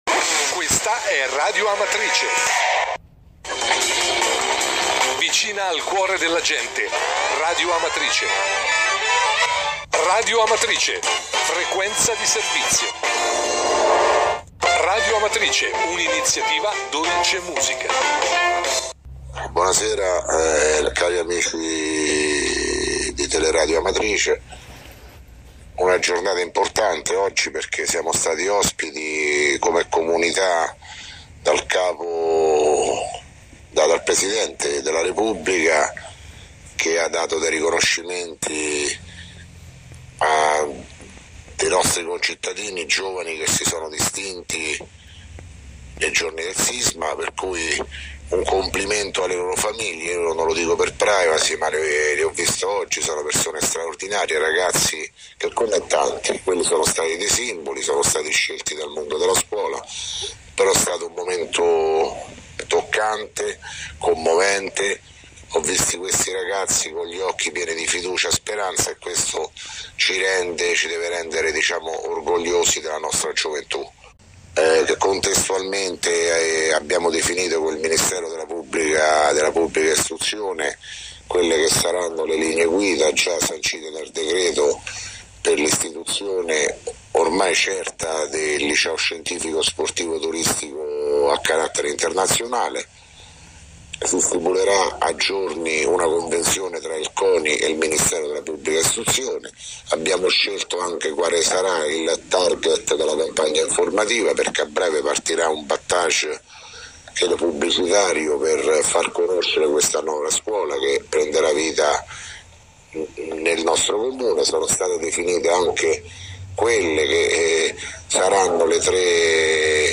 Di seguito il messaggio audio del Sindaco Sergio Pirozzi, del 13 dicembre 2016.